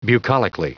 Prononciation du mot bucolically en anglais (fichier audio)
Prononciation du mot : bucolically